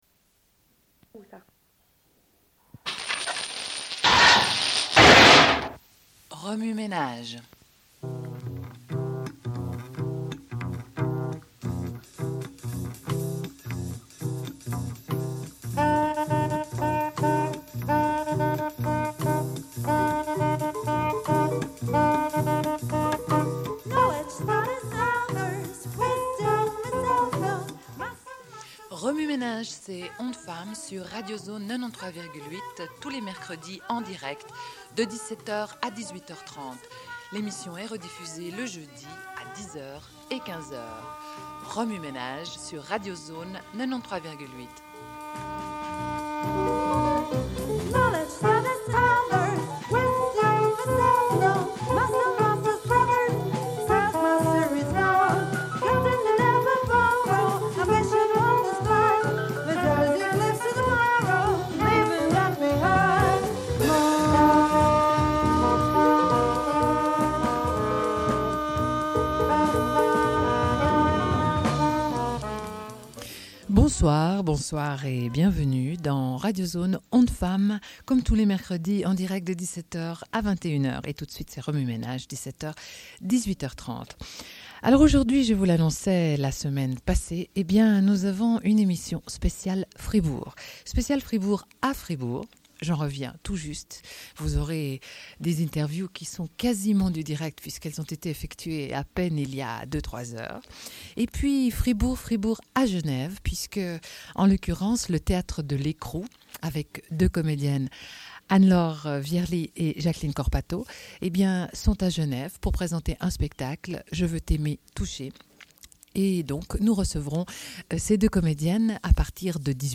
Une cassette audio, face A31:35